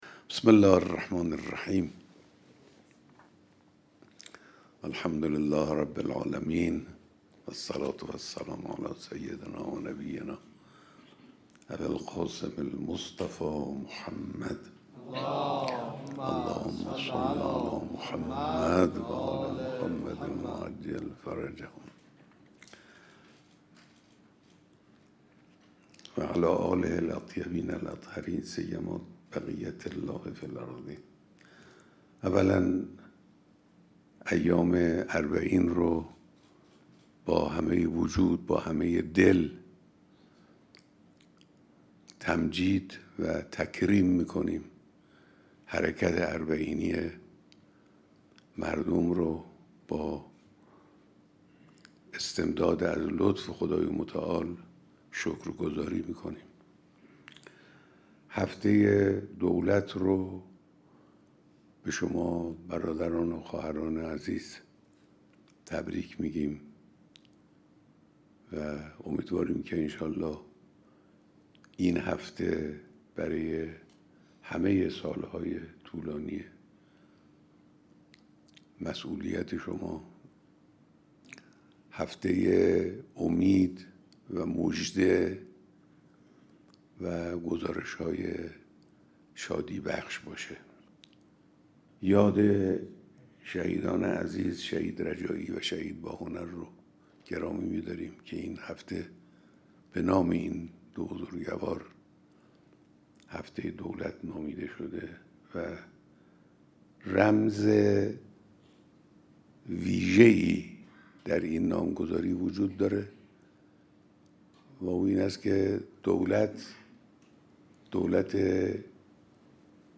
بیانات در دیدار رئیس‌جمهور و اعضای هیئت دولت چهاردهم